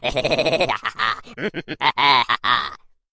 Звук хихикающего гномика